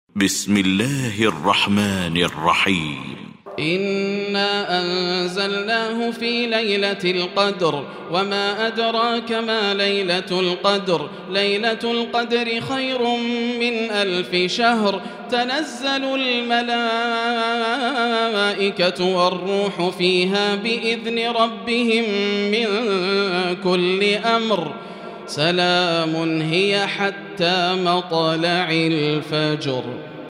المكان: المسجد الحرام الشيخ: فضيلة الشيخ ياسر الدوسري فضيلة الشيخ ياسر الدوسري القدر